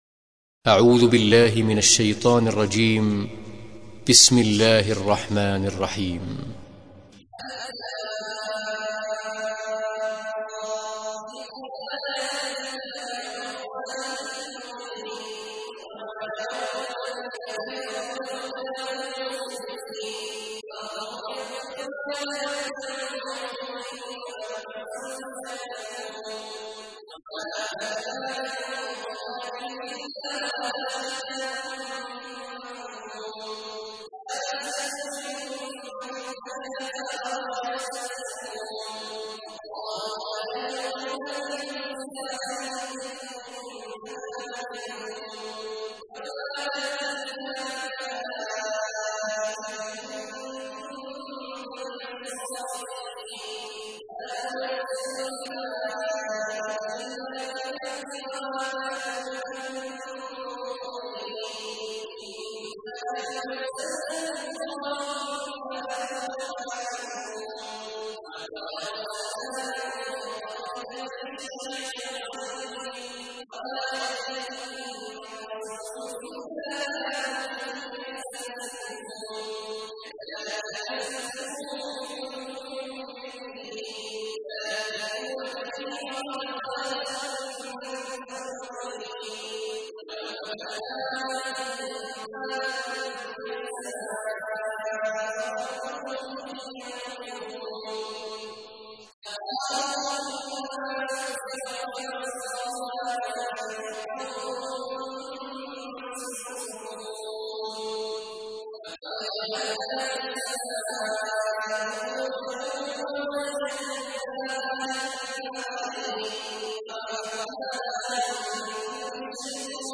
تحميل : 15. سورة الحجر / القارئ عبد الله عواد الجهني / القرآن الكريم / موقع يا حسين